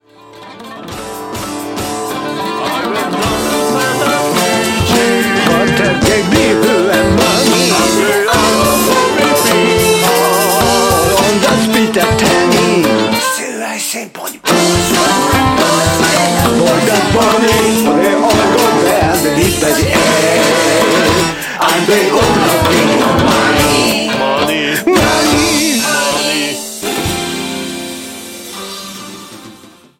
ジャンル Progressive
Rock
アバンギャルド